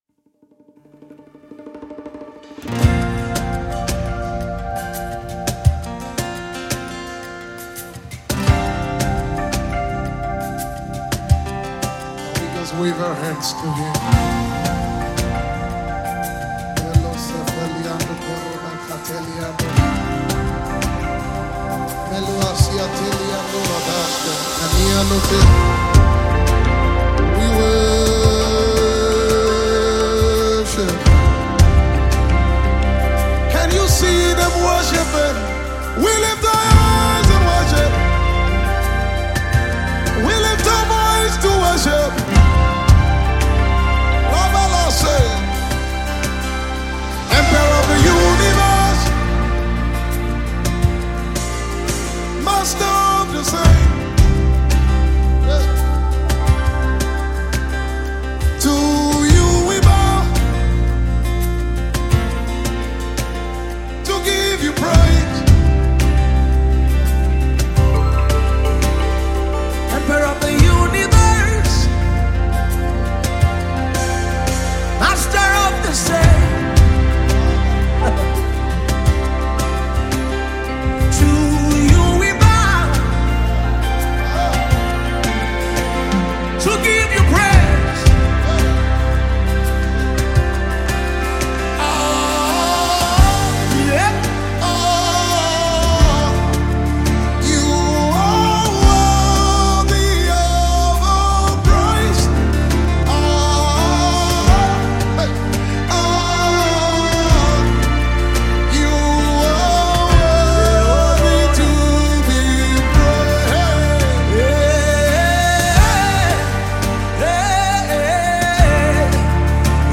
a renowned Nigerian gospel artiste